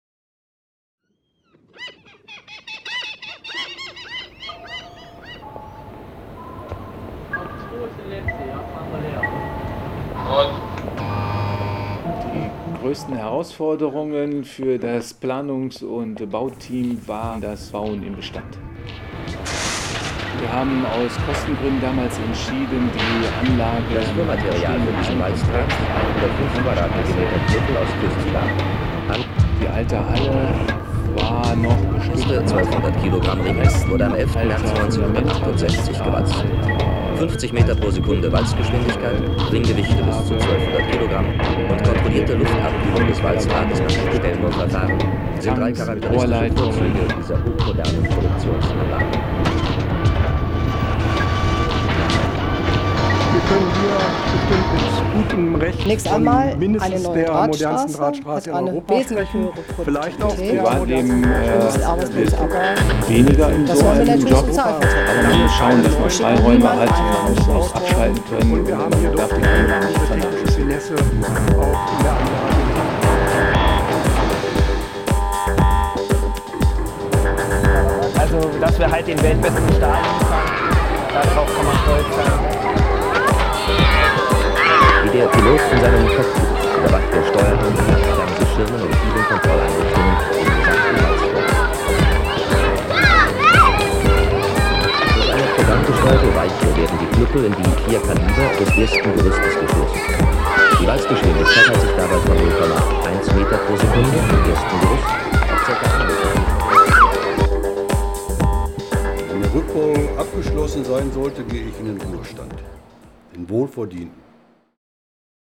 Rheinpark, Duisburg (19./20. August 2023)